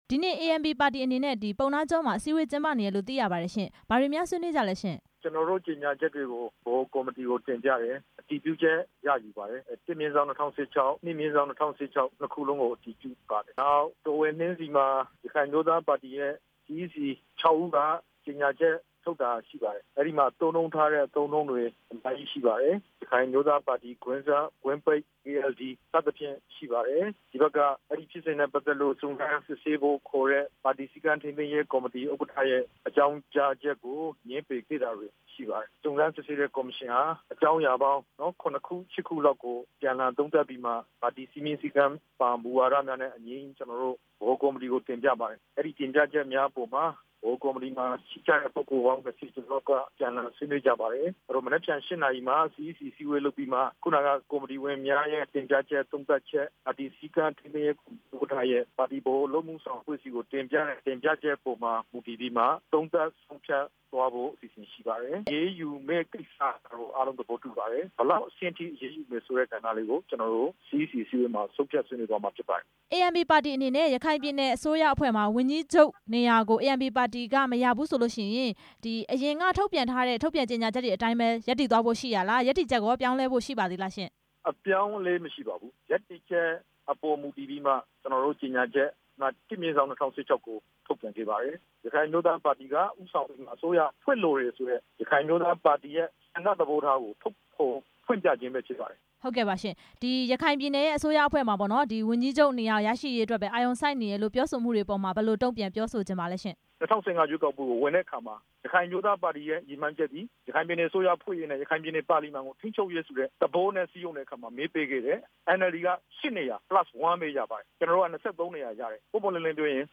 ဒေါက်တာ အေးမောင်နဲ့ မေးမြန်းချက်